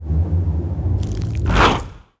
Index of /App/sound/monster/skeleton_wizard
attack_act_2.wav